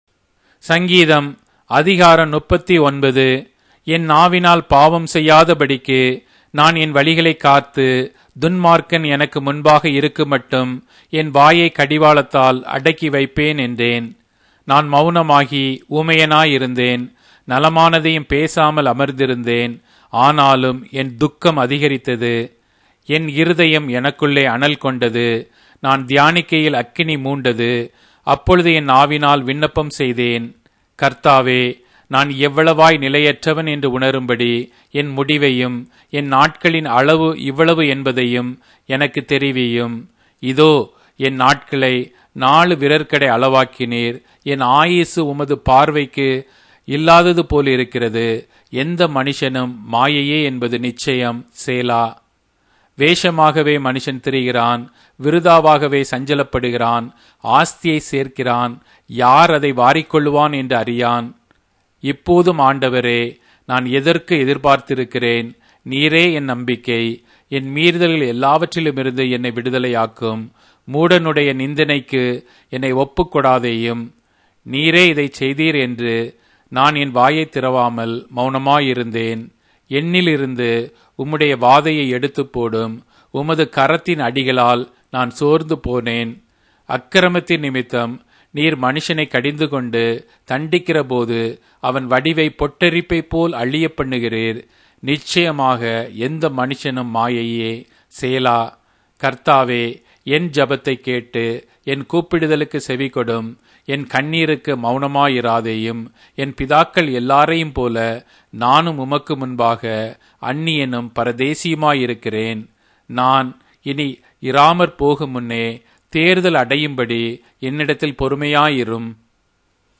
Tamil Audio Bible - Psalms 138 in Gnttrp bible version